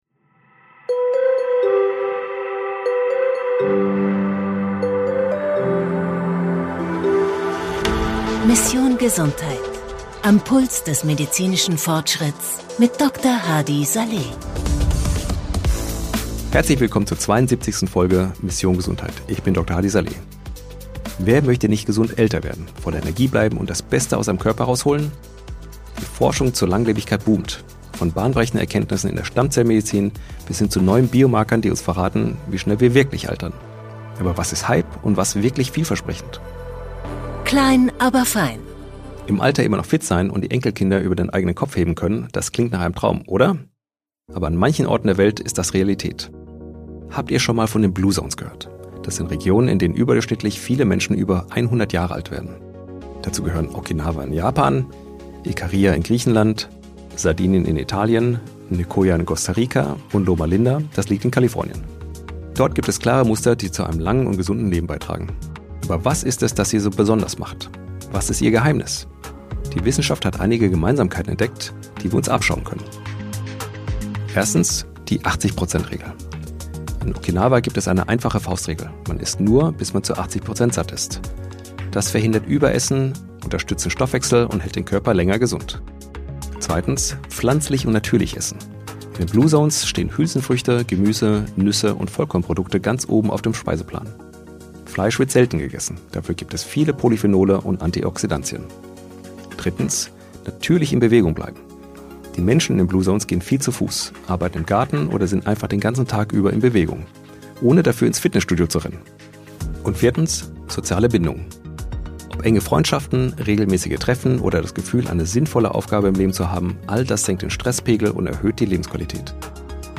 Die Forschung zur Longevity boomt, aber was ist wirklich dran? In dieser Folge von Mission Gesundheit spreche ich mit Nina Ruge, Autorin und Longevity-Expertin, über ihr neues Buch und die neuesten Erkenntnisse zur Zellalterung, epigenetischen Verjüngung und die Personalisierung von Longevity-Strategien.